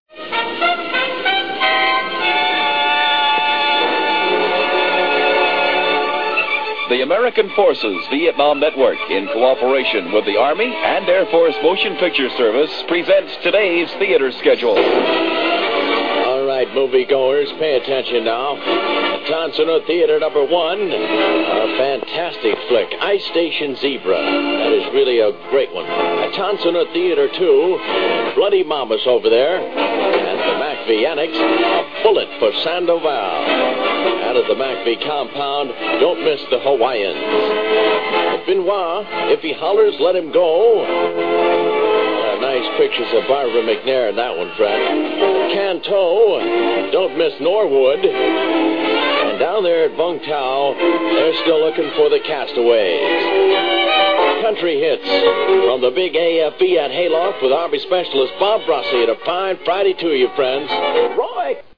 public service announcements
AFVN theather schedule announcement, 1970, MP3, 177k